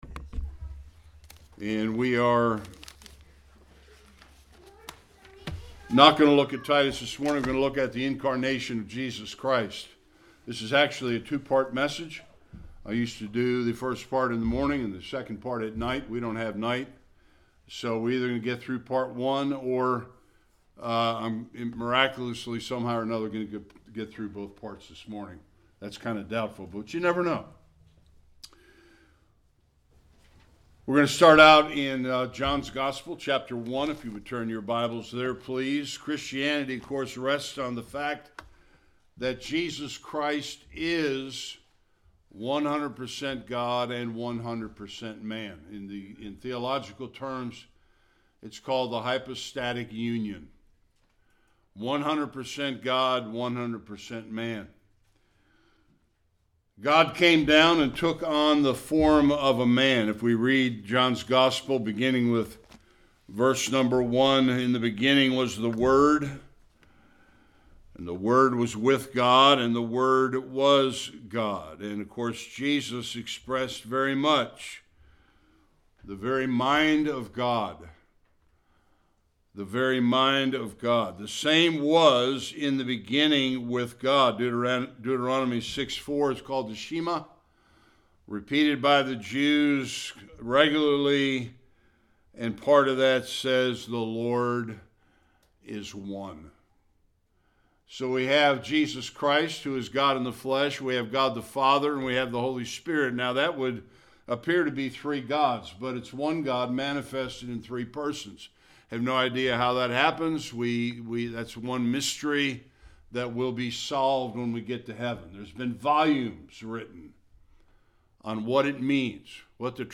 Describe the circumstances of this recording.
Various Passages Service Type: Sunday Worship What does the Bible say about the incarnation of Christ?